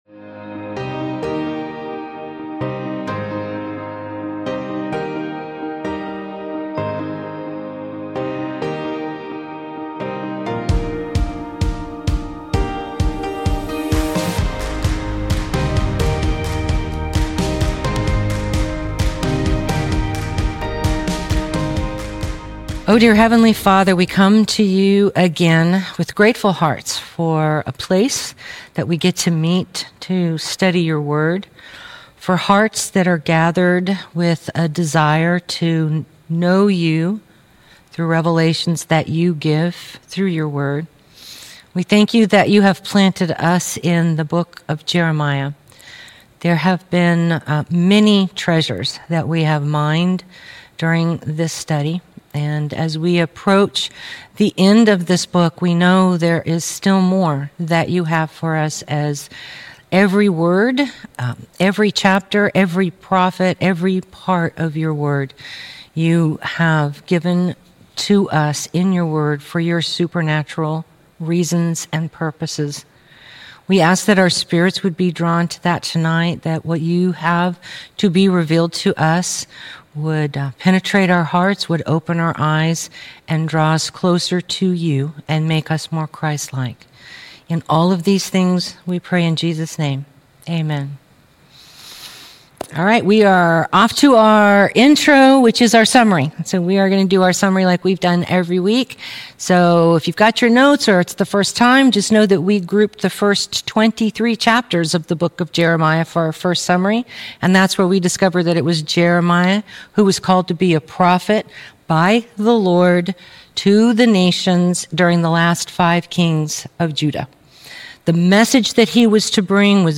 Jeremiah - Lesson 51B | Verse By Verse Ministry International